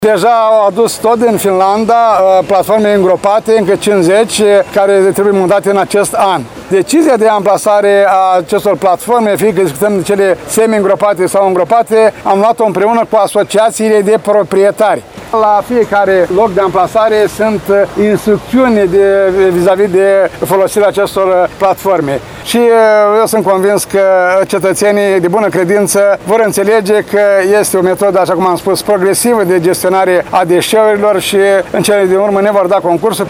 Primarul ION LUNGU a declarat că este vorba despre “un moment revoluționar” în ceea ce privește gestionarea deșeurilor și a precizat că investițiile ce vor fi realizate în perioada de contract de 12 ani se ridică la valoarea de 9 milioane euro.